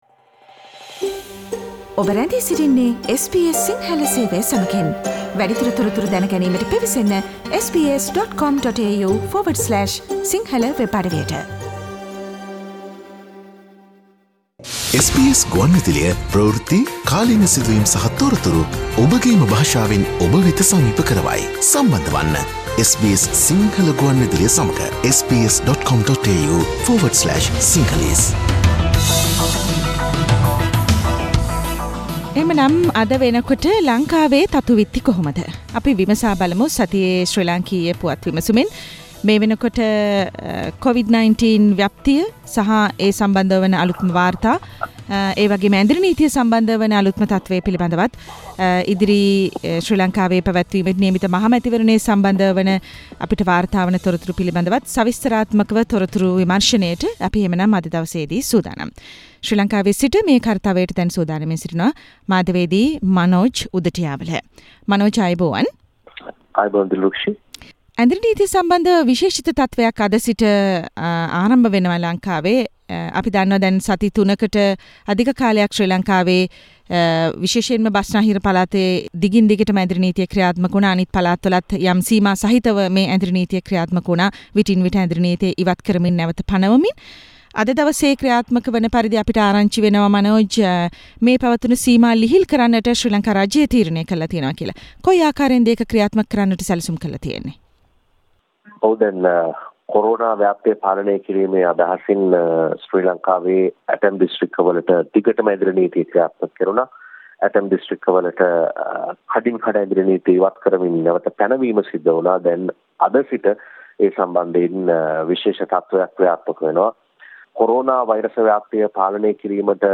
Sri Lankan weekly news wrap Source: SBS Sinhala radio